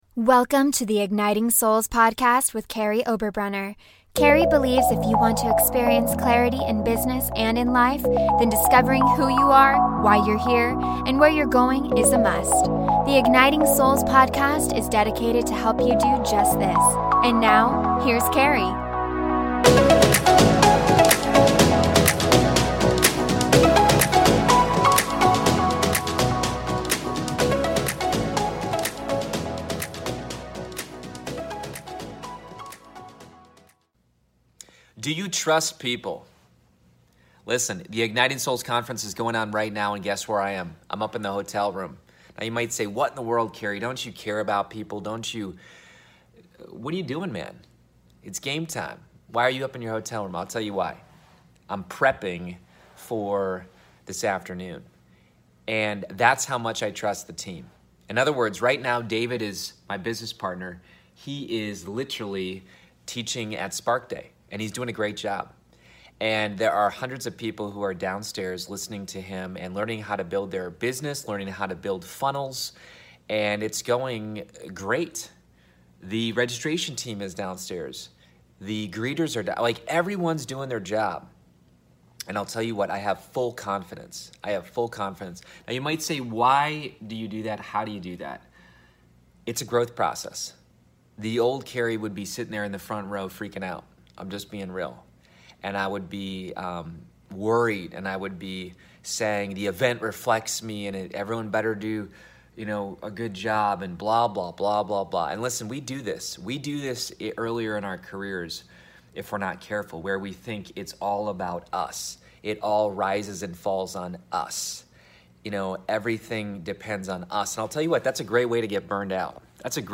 The Igniting Souls Conference is going on right now, and I'm in my hotel room. I'm prepping for this afternoon.